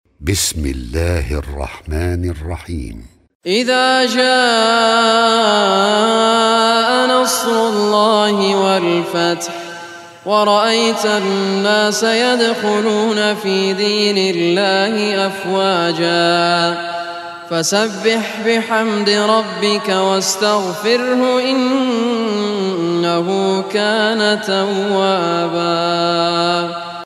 Surah an-Nasr Recitation by Sheikh Raad Kurdi
Surah an-Nasr, listen online recitation in the beautiful voice of Sheikh Raad Al Kurdi.